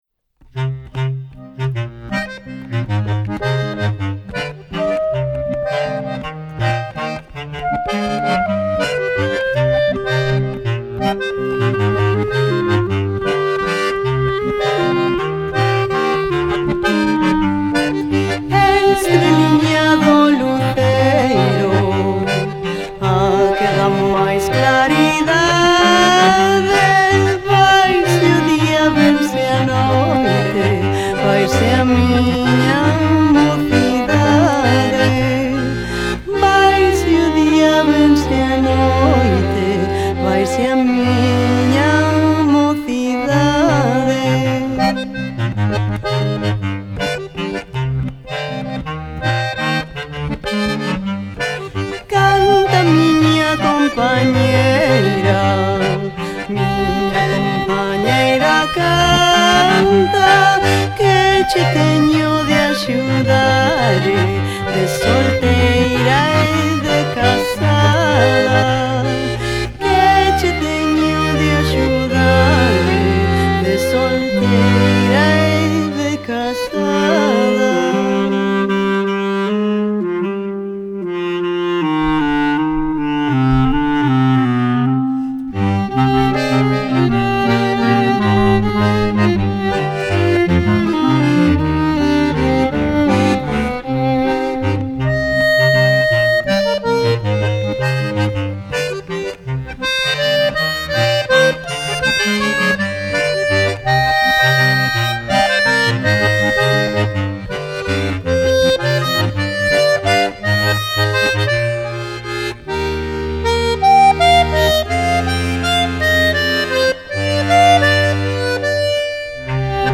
Acordeón Diatónico
Eu emprego un acordeón de tres ringleiras co sistema de teclado de Bruno Le Tron.
A diferencia do acordeón cromático, o diatónico é bisonoro, é dicir, a nota do mesmo botón cambia dependendo se o aire sae ou entra,  se o fol fecha ou abre.